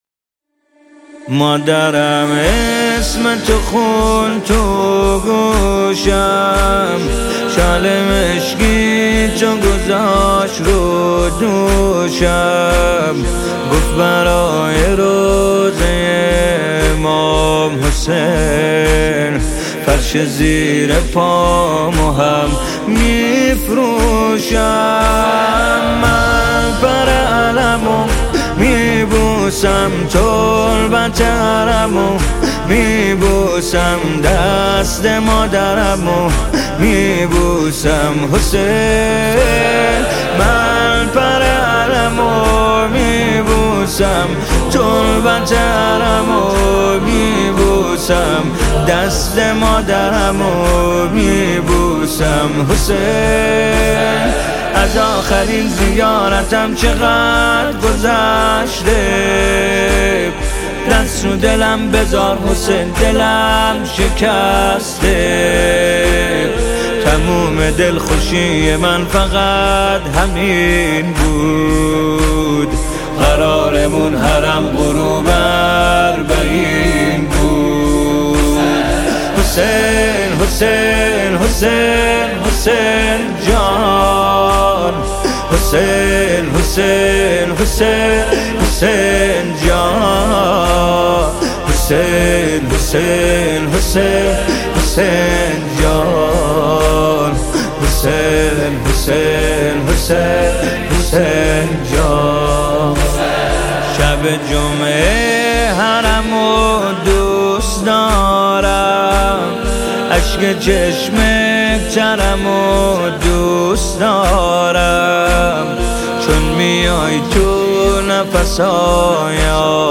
مداحی محرم